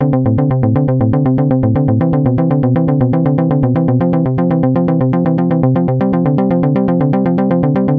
三重低音3
描述：这有一种和弦的感觉。但仍然是低音。（与之前的音符相同） 3 of 3
Tag: 120 bpm Electro Loops Bass Loops 1.35 MB wav Key : D